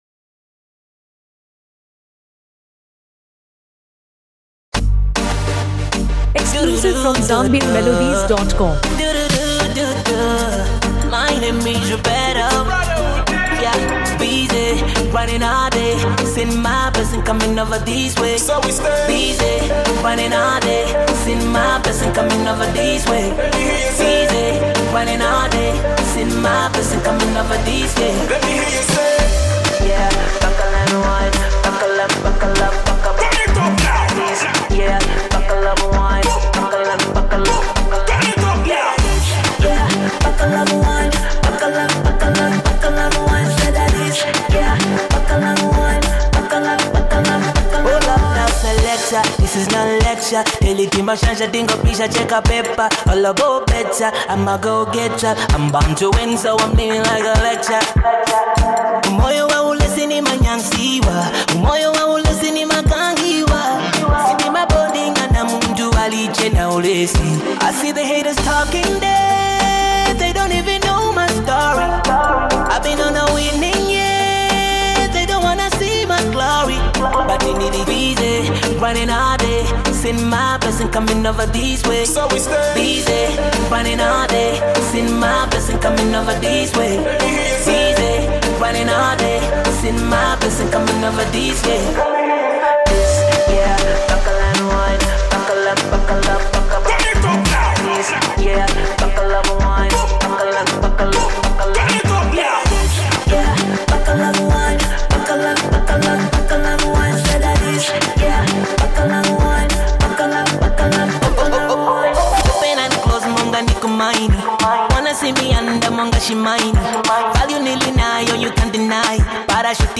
Trending Zambian Afro-Fusion Track
a refreshing Afro-Fusion anthem